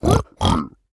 Minecraft Version Minecraft Version 25w18a Latest Release | Latest Snapshot 25w18a / assets / minecraft / sounds / mob / piglin / celebrate4.ogg Compare With Compare With Latest Release | Latest Snapshot
celebrate4.ogg